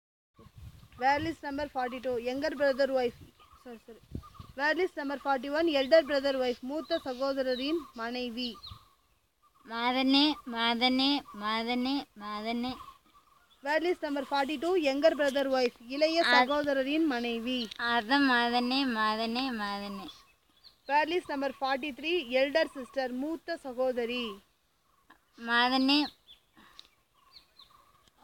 Elicitation of words for kinship terms - Part 9